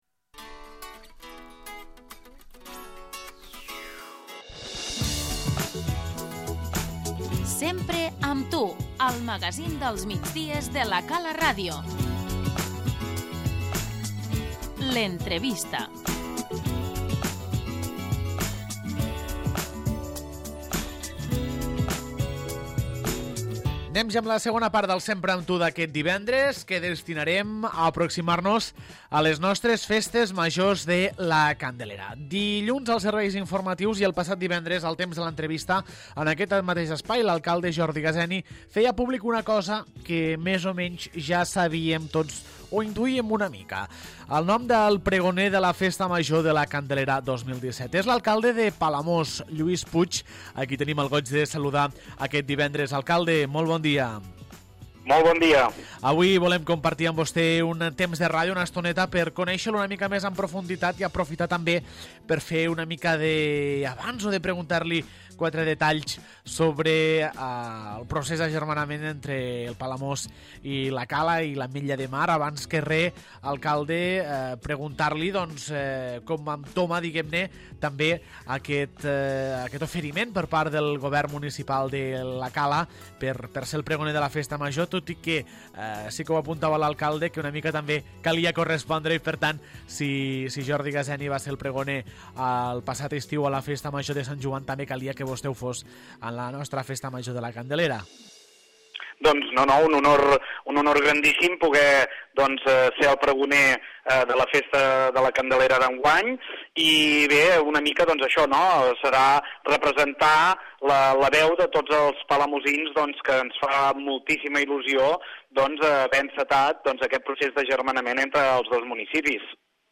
L'entrevista - Lluís Puig, alcalde de Palamós